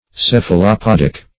Search Result for " cephalopodic" : The Collaborative International Dictionary of English v.0.48: Cephalopodic \Ceph`a*lo*pod"ic\ (s[e^]f`[.a]*l[-o]*p[o^]d"[i^]k), Cephalopodous \Ceph`a*lop"o*dous\, a. (Zool.) Belonging to, or resembling, the cephalopods.
cephalopodic.mp3